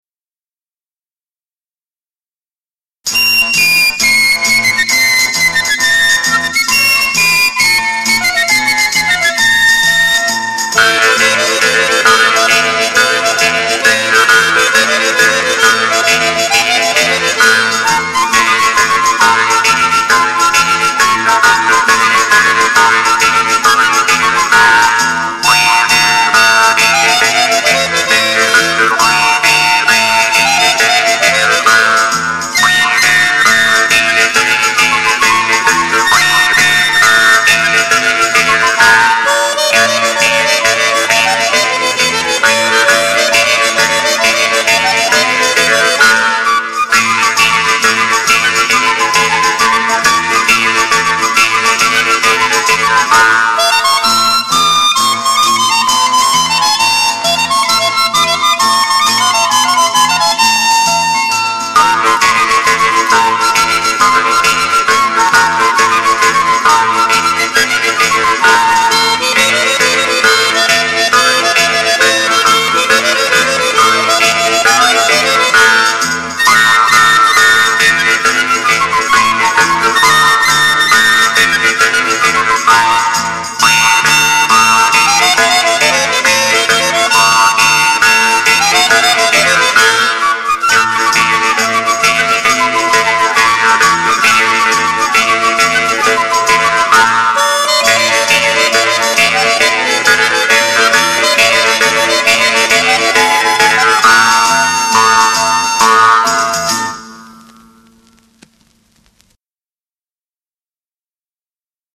(SCACCIAPENSIERI - JEW' S HARP)